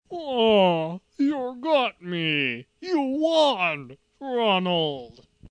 incredible dramatization.mp3